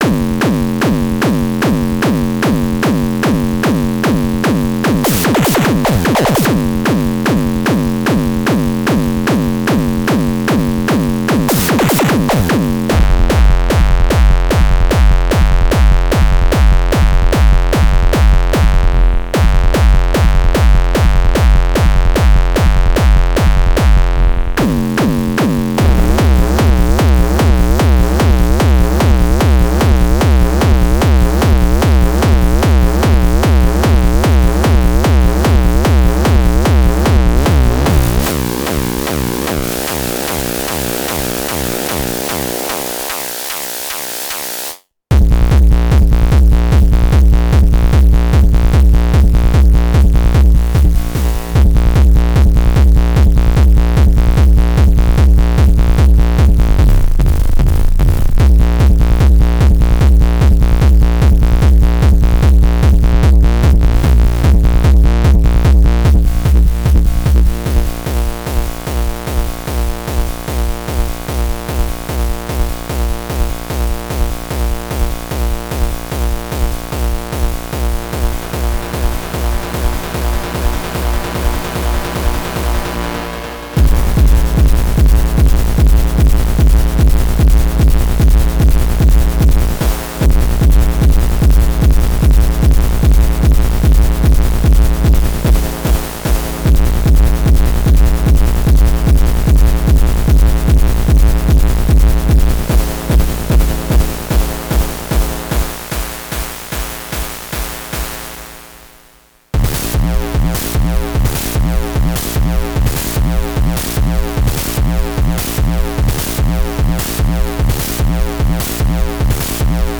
I’ll refrain from posting this to the syntakt only music thread, as it was about pushing one SY BITS into different kinds of bangin territory. Inspired by recent noise posts and a dutch childhood.